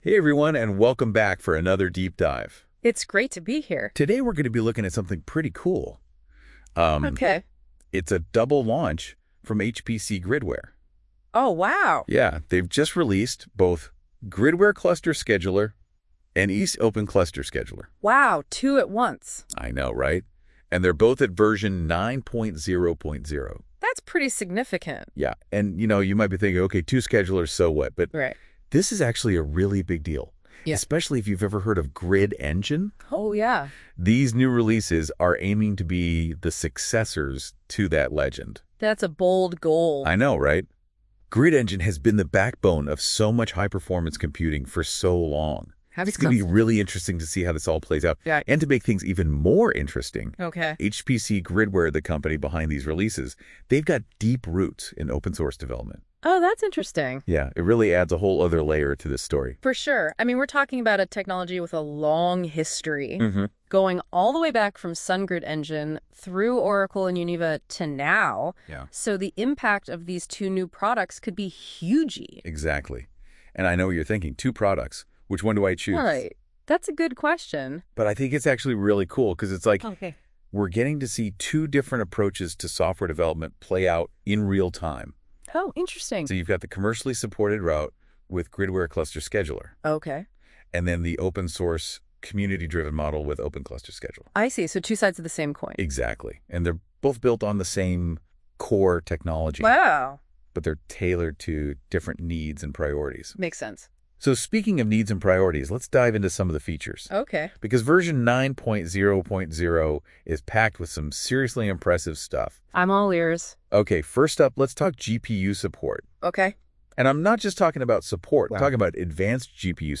I couldn't resist using NotebookLM to create a podcast about our first releases: the Open Cluster Scheduler and the Gridware Cluster Scheduler.
Creating this podcast was a five-minute task—simply uploading the blog posts about the Open Cluster Scheduler and the Gridware Cluster Scheduler and letting the conversation be generated.